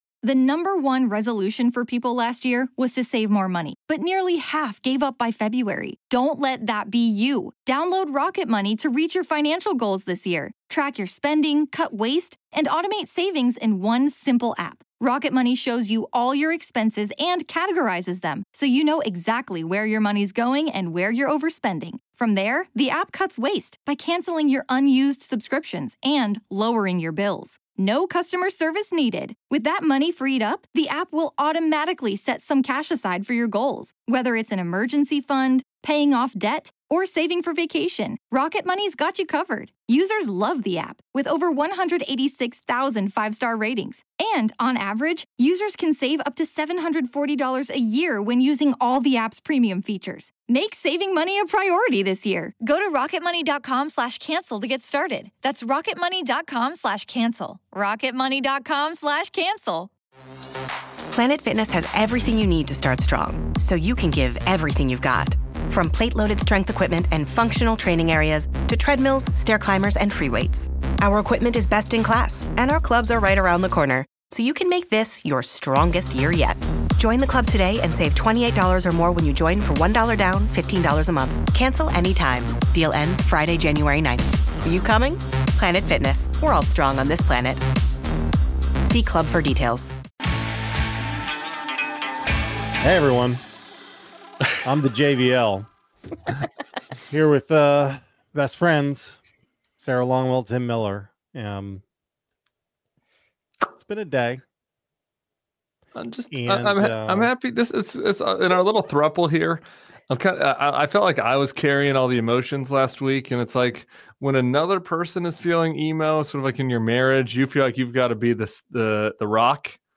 The gang went live Wednesday night to discuss RFK Jr.’s Senate hearing, the farce that was the questioning from Republicans, Trump’s funding freeze debacle, the first White House press briefing, and whether any of these things matter one bit.